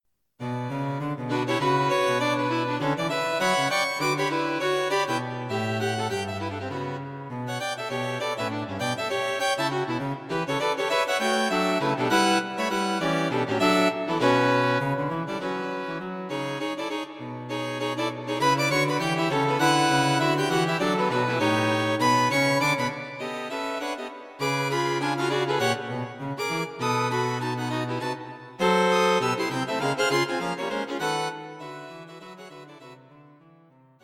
String Quartet
Written for String Quartet.